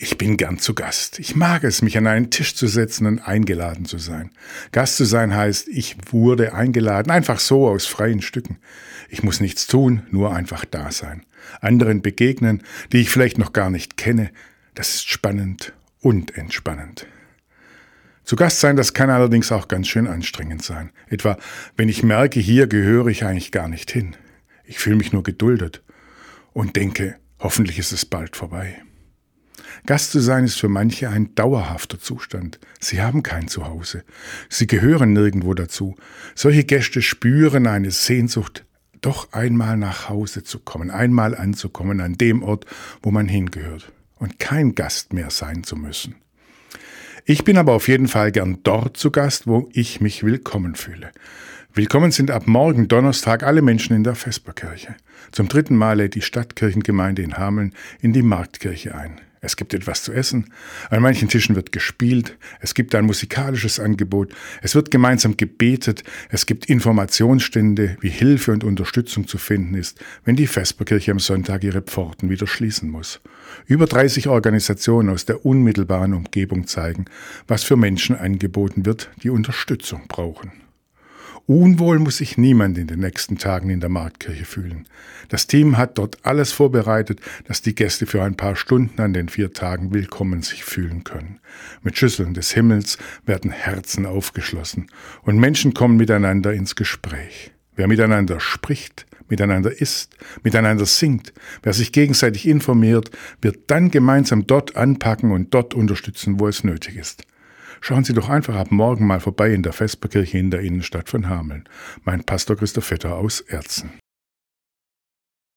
Radioandacht vom 15. Oktober